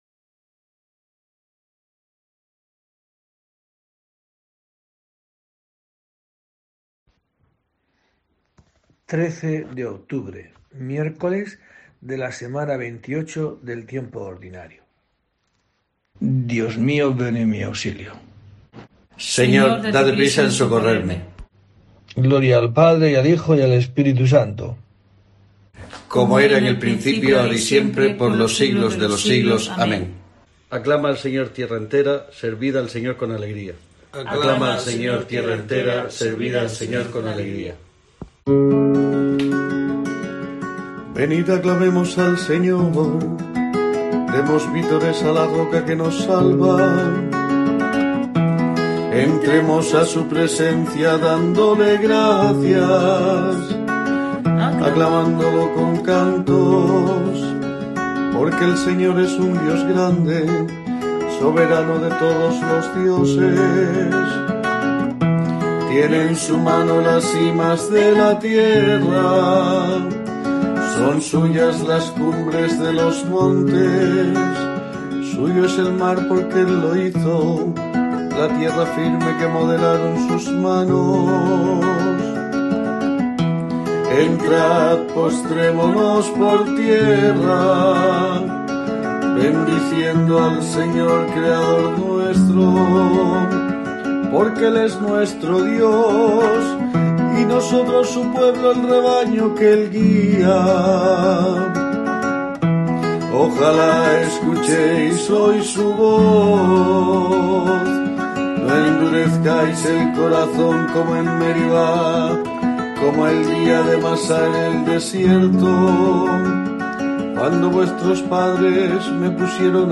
En este día tiene lugar una nueva oración en el rezo de Laudes, marcado por la nueva normalidad.